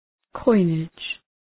Προφορά
{‘kɔınıdʒ}